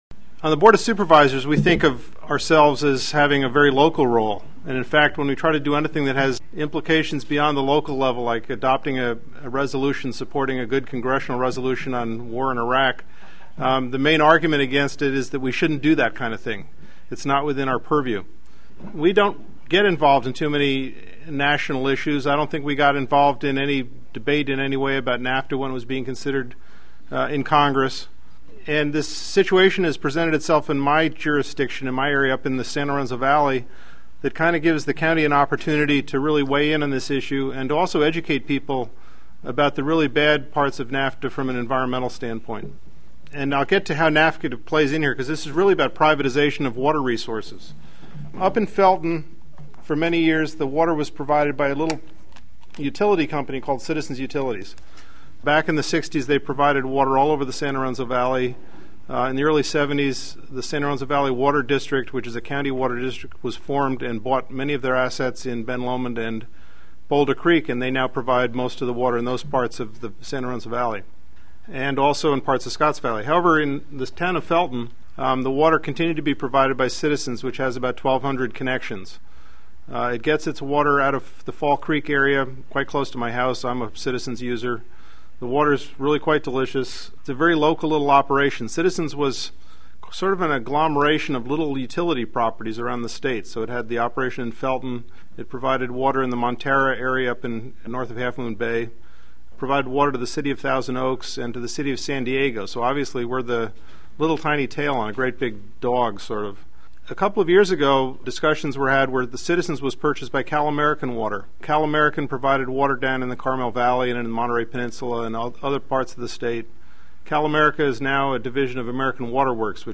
Santa Cruz Indymedia: Jeff Almquist Talk
jeff_almquist.mp3 (0 k) On October 7 a coalition of groups promoting fair trade came to Santa Cruz to participate in a public hearing on the effects of the FTAA. Santa Cruz County Supervisor Jeff Almquist spoke about the threat of water privatization in Felton 48 kbps 13:15